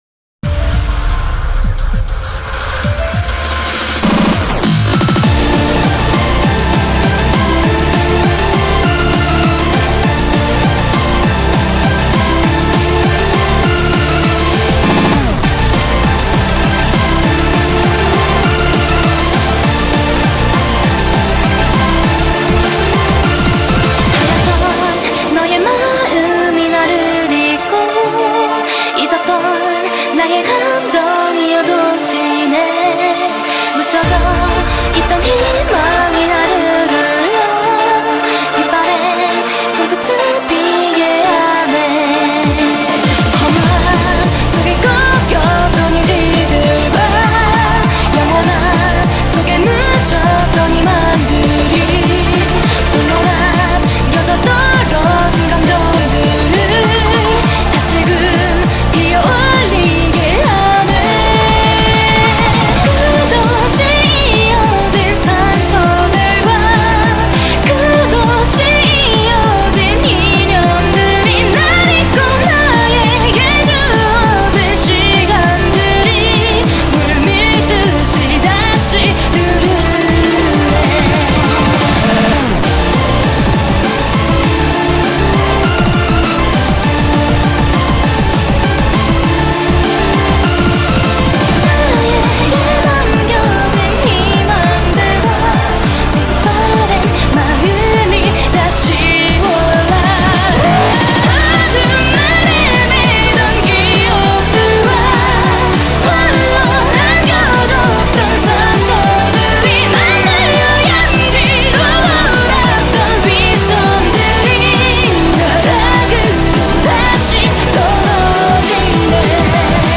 BPM100-200
Audio QualityMusic Cut